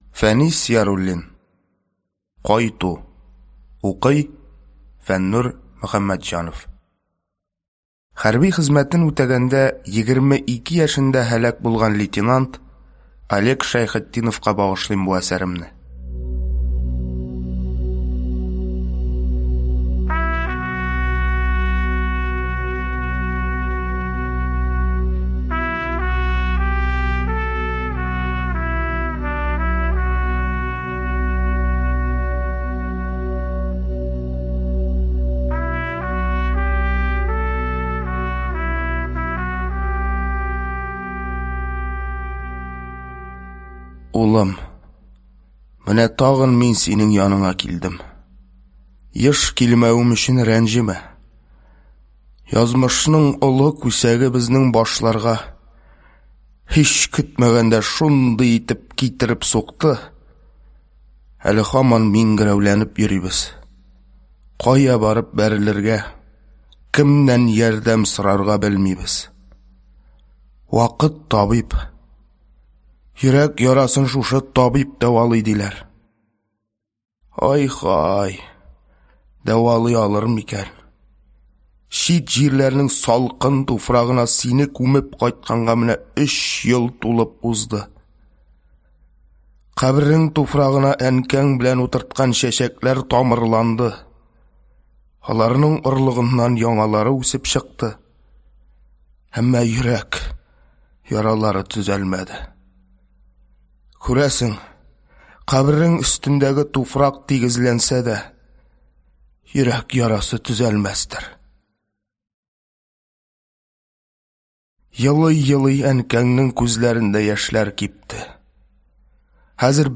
Аудиокнига Кайту | Библиотека аудиокниг
Прослушать и бесплатно скачать фрагмент аудиокниги